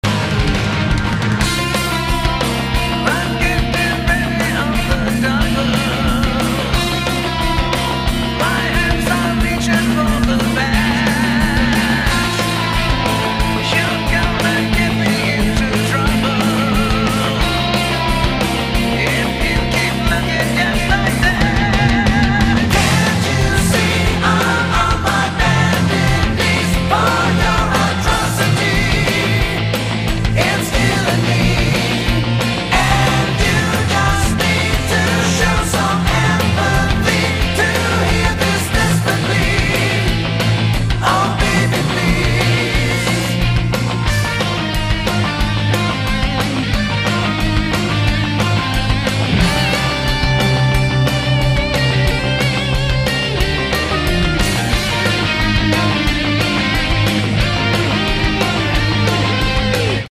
massive big background vocals.
keyboards, bass, guitars
lead and background vocals
drums
acoustic guitar
guitar solos